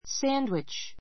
sandwich 小 A1 sǽn(d)witʃ サ ン (ド) ウィ チ 名詞 サンドイッチ make sandwiches for a picnic make sandwiches for a picnic ピクニックのためのサンドイッチを作る I want two BLT sandwiches to go.